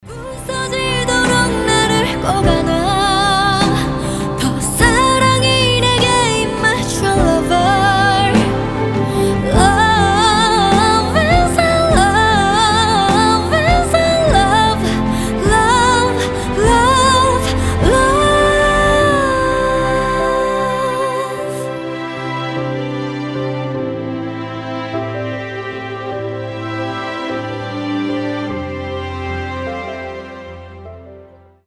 Громкие Красивый женский голос